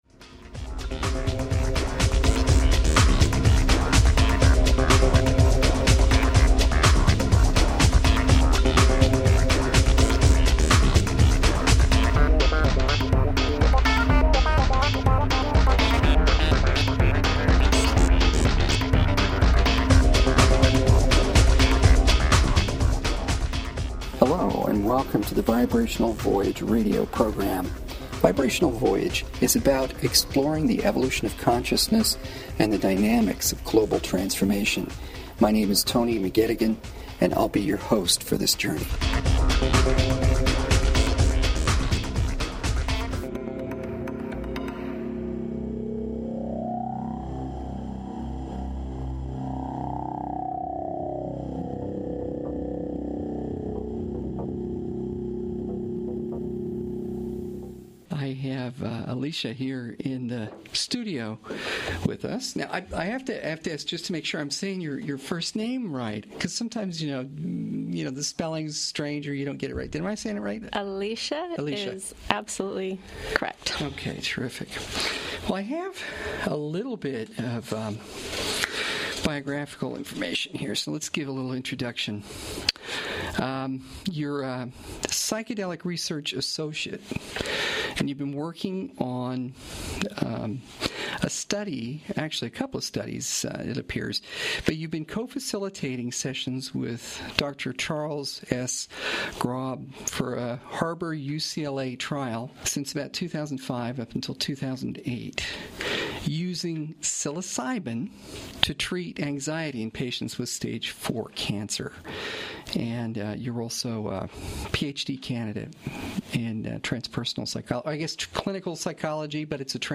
Vibrational Voyage is a live, weekly radio program devoted to the exploration of consciousness. Interviews and presentations with adventurers of the inner landscape.